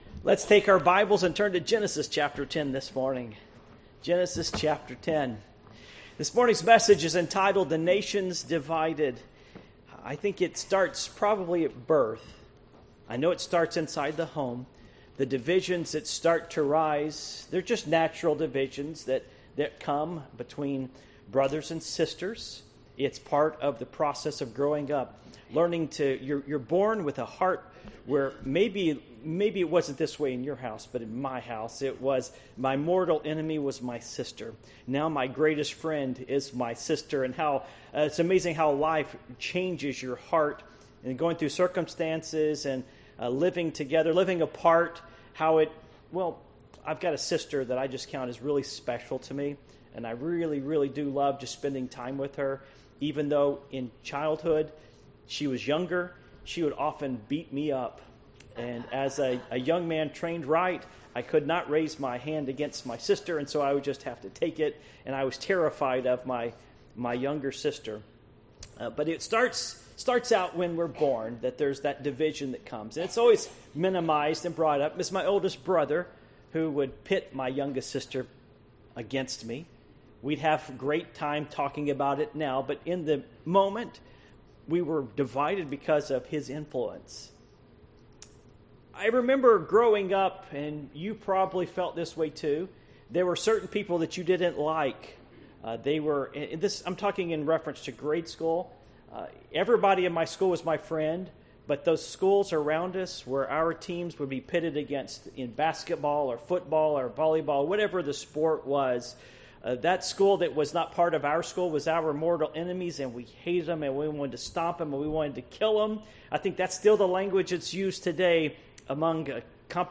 Passage: Genesis 10:1-32 Service Type: Morning Worship